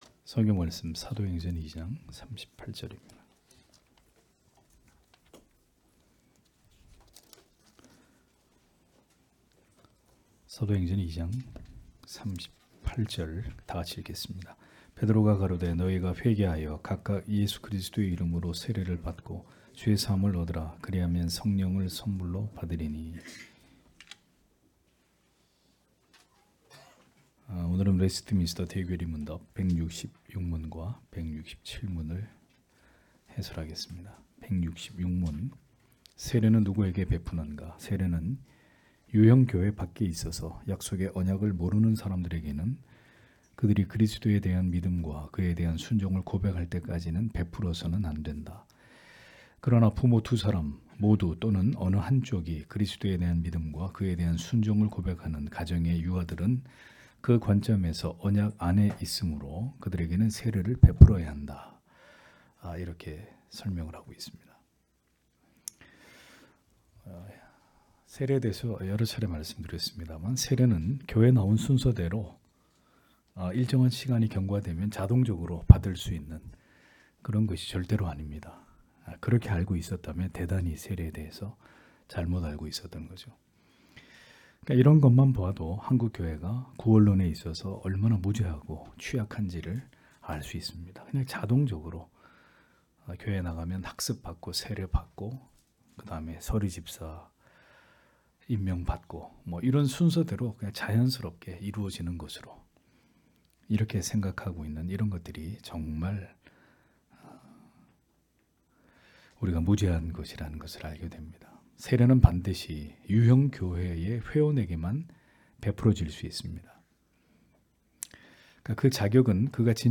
주일오후예배 - [웨스트민스터 대요리문답 해설 166-167 ] 166문) 누구에게 세례를 베풀게 되는가? 167문) 우리의 세례를 어떻게 잘 효용할 수 있는가? (사도행전 2장 38절)
* 설교 파일을 다운 받으시려면 아래 설교 제목을 클릭해서 다운 받으시면 됩니다.